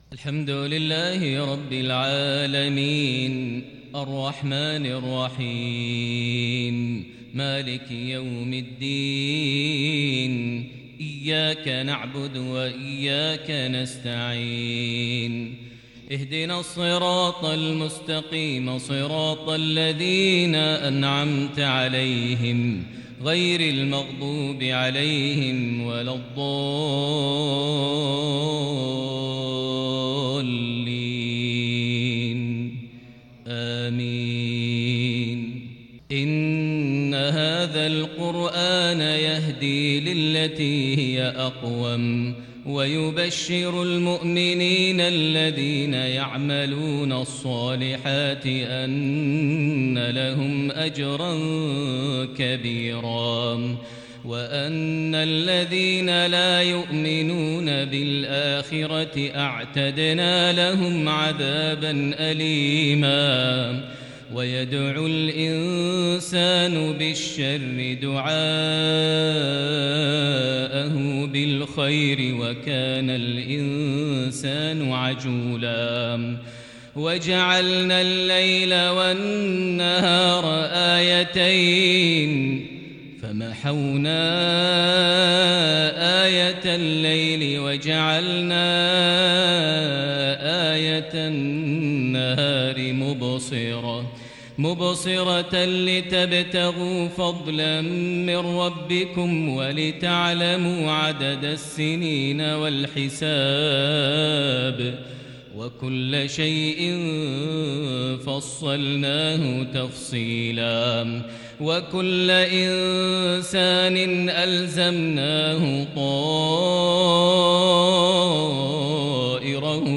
صلاة المغرب للشيخ ماهر المعيقلي 19 صفر 1442 هـ
تِلَاوَات الْحَرَمَيْن .